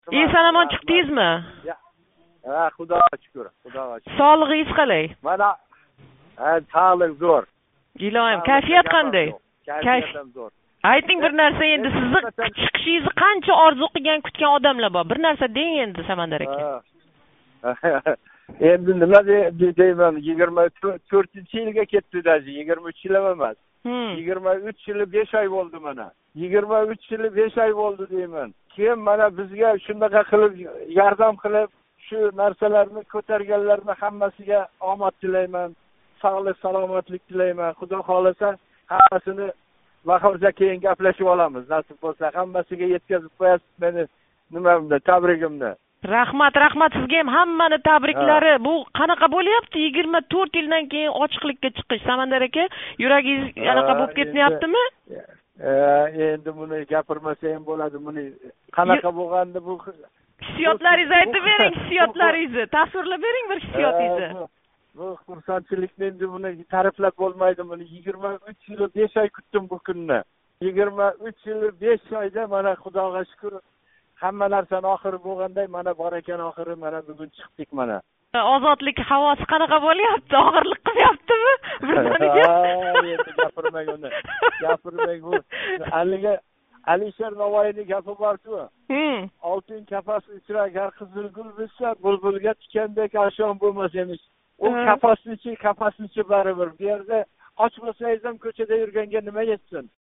Ўзбекистон қамоғида энг узоқ сақланган сиëсий маҳбус Қўқонов Зарафшон қамоқхонасидан озодликка чиқиши билан Озодликка гапирди.
Самандар Қўқонов билан илк суҳбат